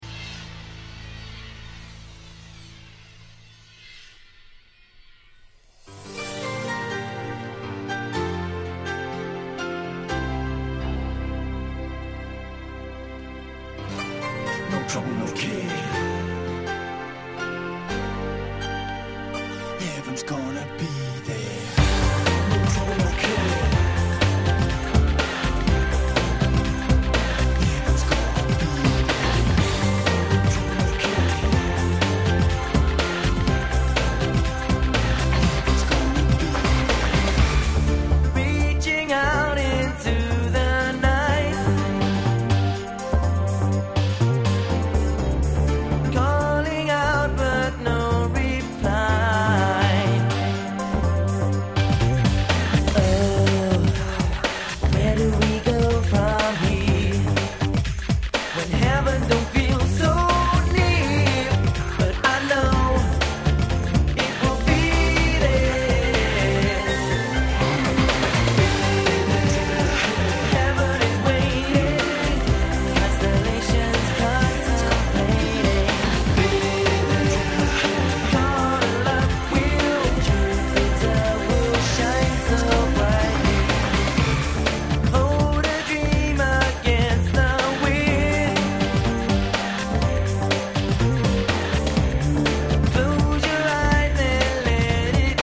94' Nice EU R&B !!